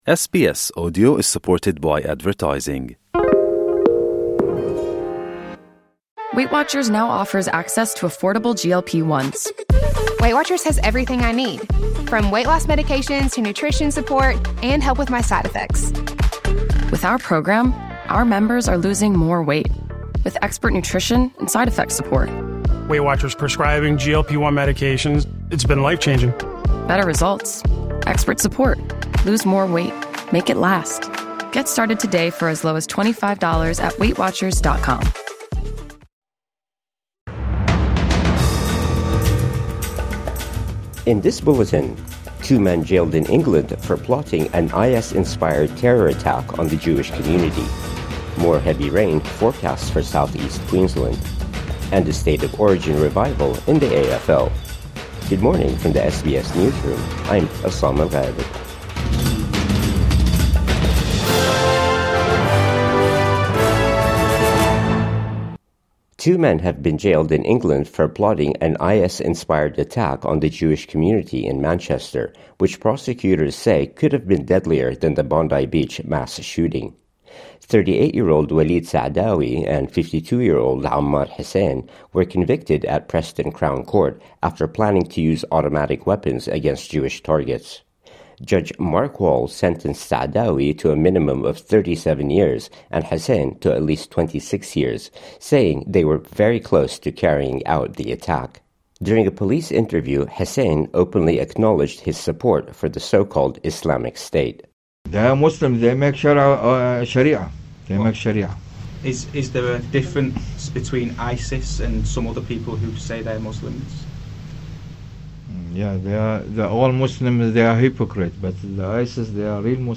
Two UK men sentenced over plot to target Jewish community | Morning News Bulletin 14 February 2026